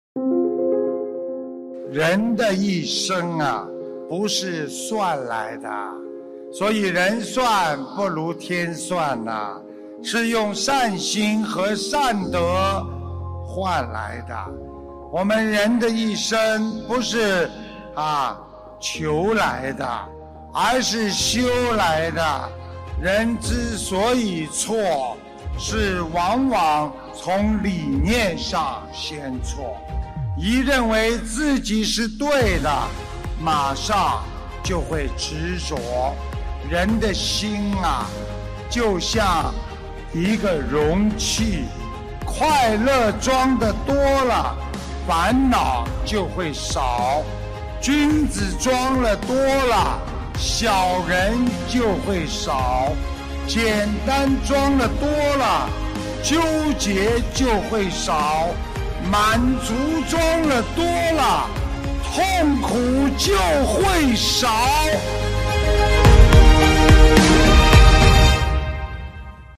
—— 2015年1月24日 马来西亚 槟城法会开示